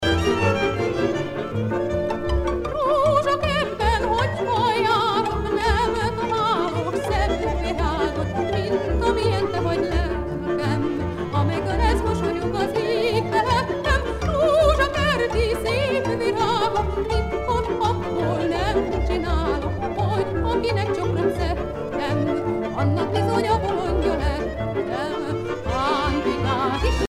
danse : csárdás (Hongrie)
Pièce musicale éditée